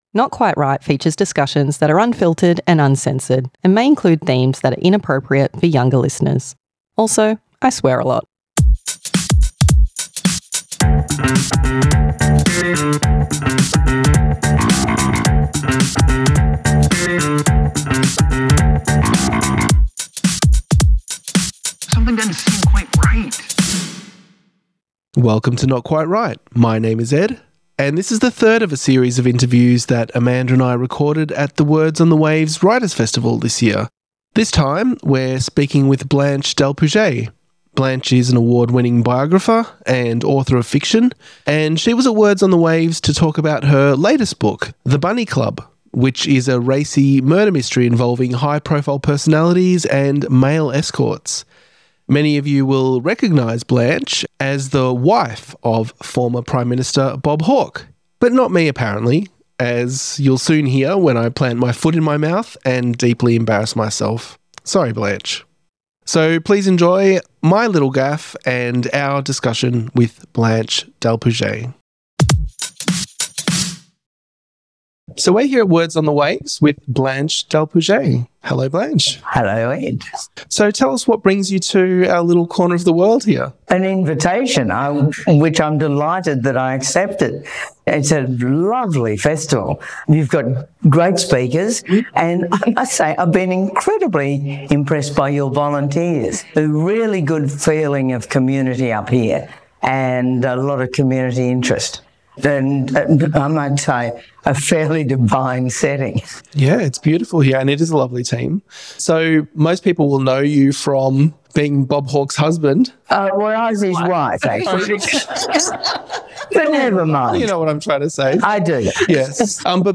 Interview with Blanche d’Alpuget at Words on the Waves 2025 - Not Quite Write Podcast